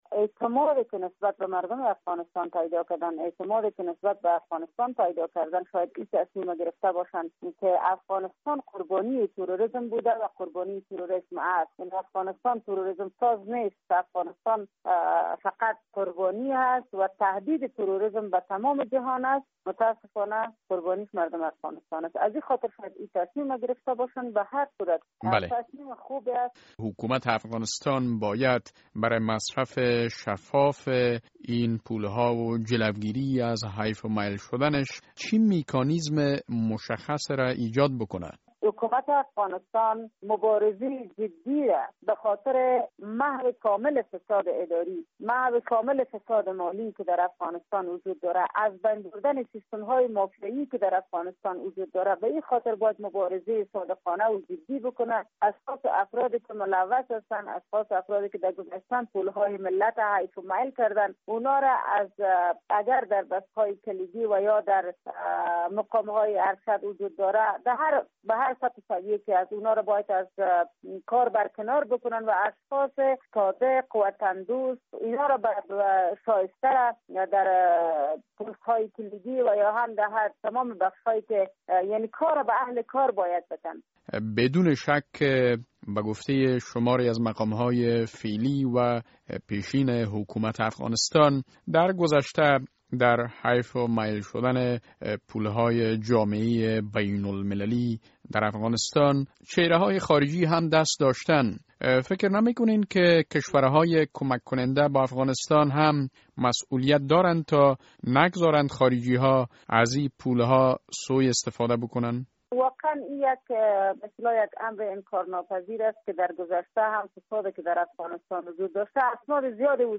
گفتگو با فاطمه عزیز، عضو ولسی جرگۀ افغانستان: